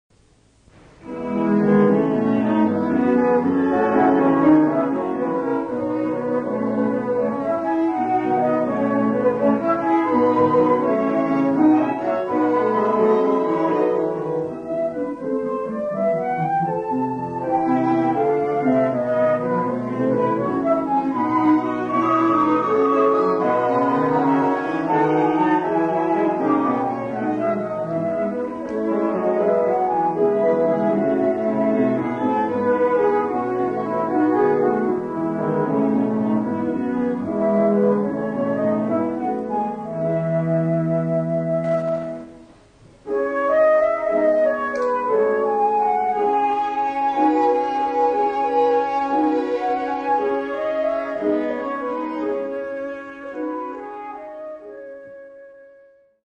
Muchas grabaciones que aquí se ofrecen se registraron en presentaciones en vivo durante las décadas de 1950, 1960 y 1970.
Divertimento para flauta, violín, violoncello y piano (1959).